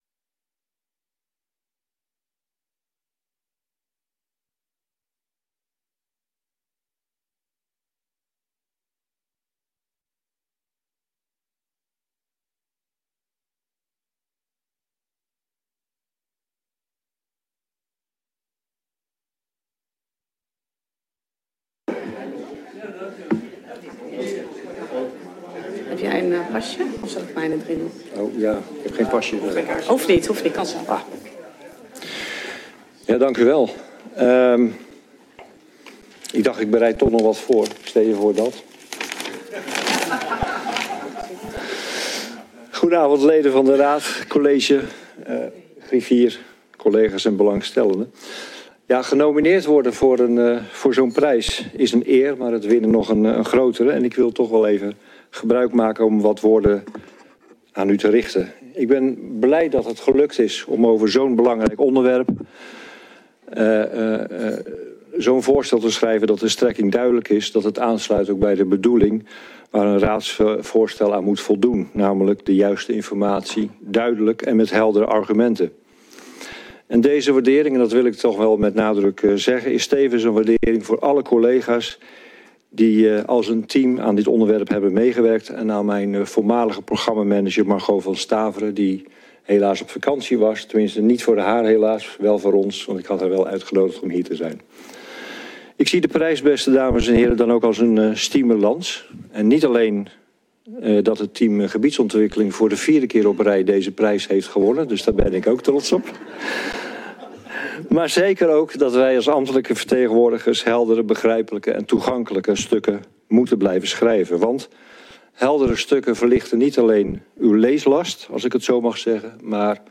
Raadsvergadering 29 februari 2024 19:30:00, Gemeente Dronten
Locatie: Raadzaal